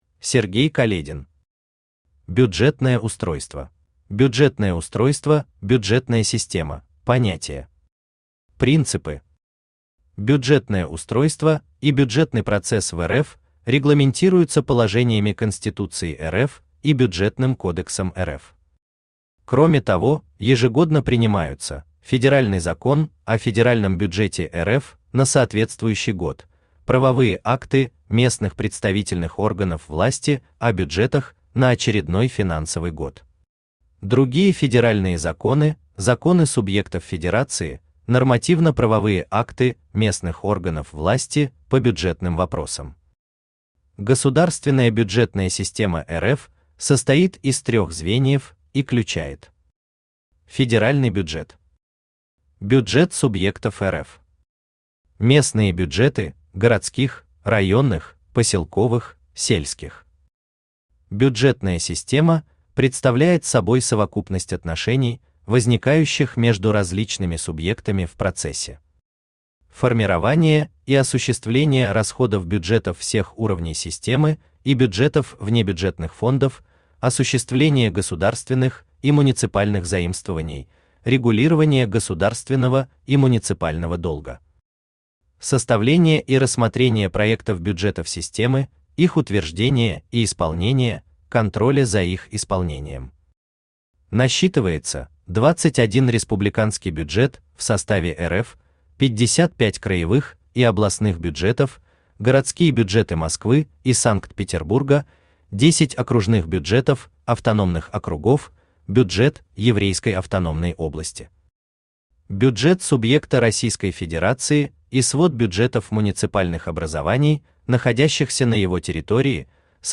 Аудиокнига Бюджетное устройство | Библиотека аудиокниг
Aудиокнига Бюджетное устройство Автор Сергей Каледин Читает аудиокнигу Авточтец ЛитРес.